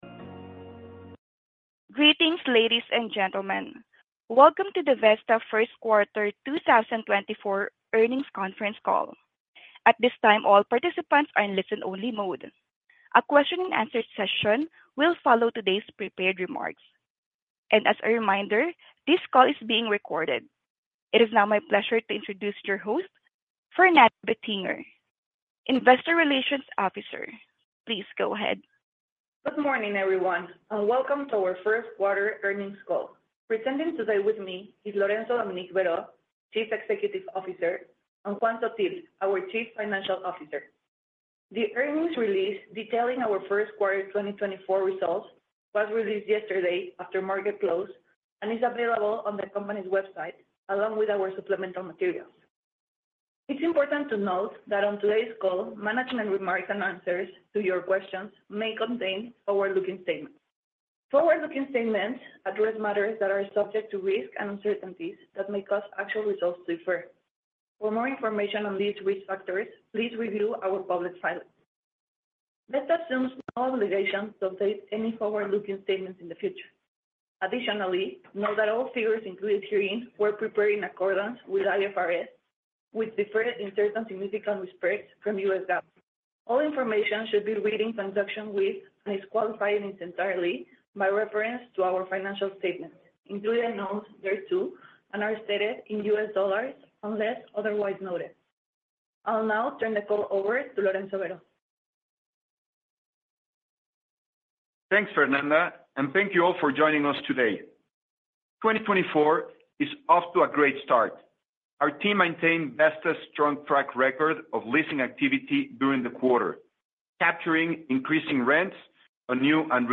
Q1+2024+Earnings+Call.mp3